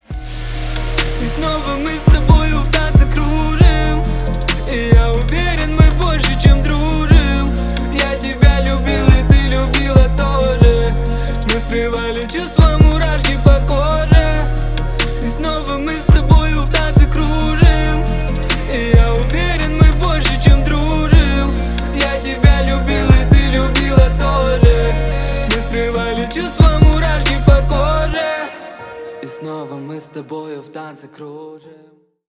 • Качество: 320 kbps, Stereo
Поп Музыка
грустные
тихие